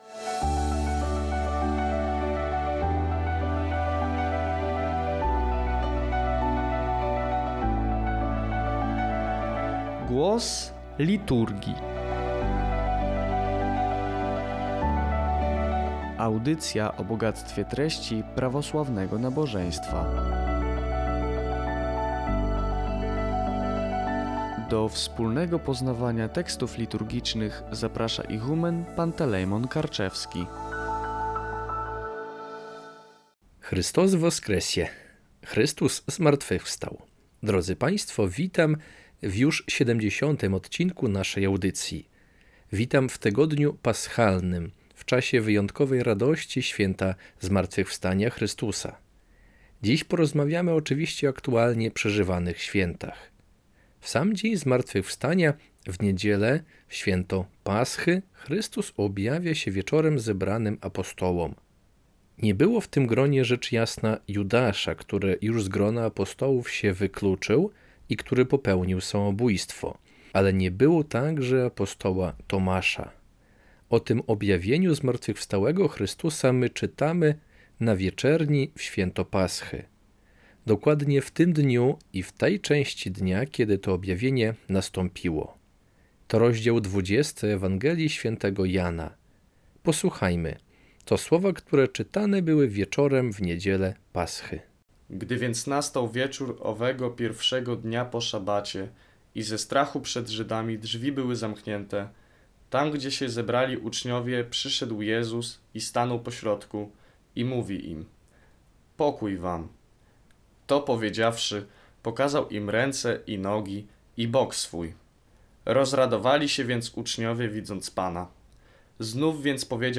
Audycja „Głos Liturgii” co dwa tygodnie.